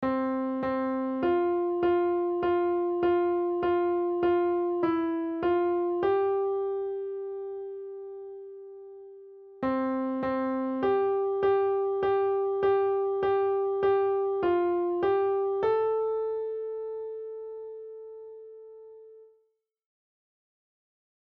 On the piano, play
C C F F F F F F